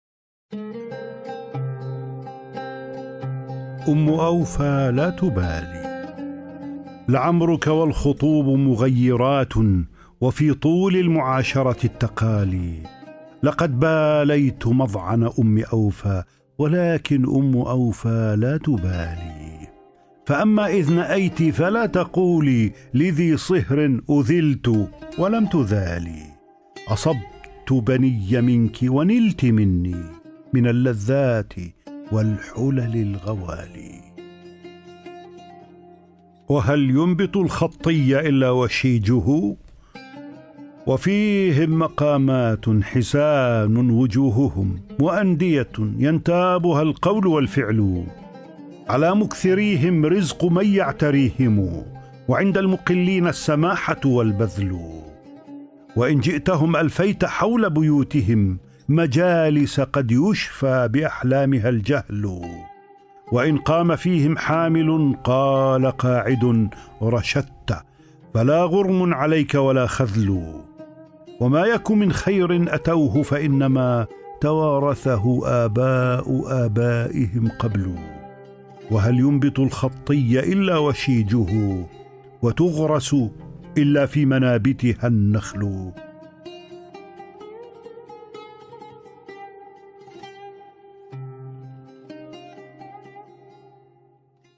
الكتب المسموعة